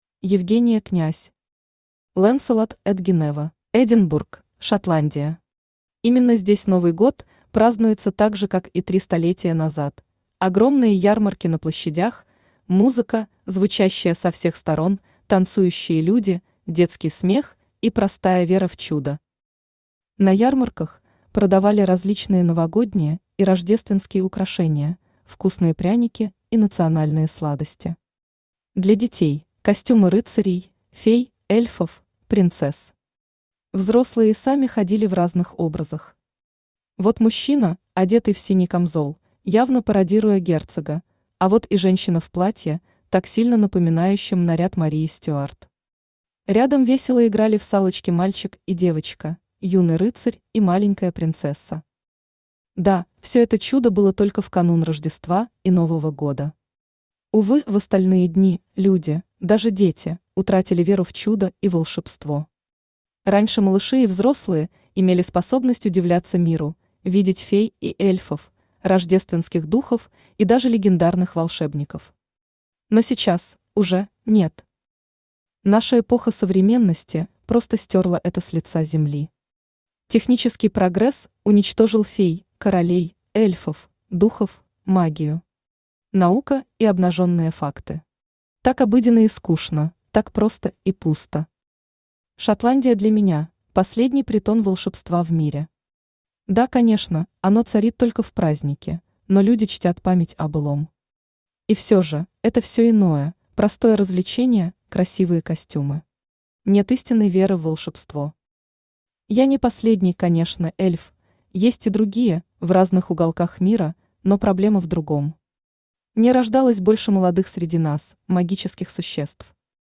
Аудиокнига Lancelot et Guinevere | Библиотека аудиокниг
Читает аудиокнигу Авточтец ЛитРес.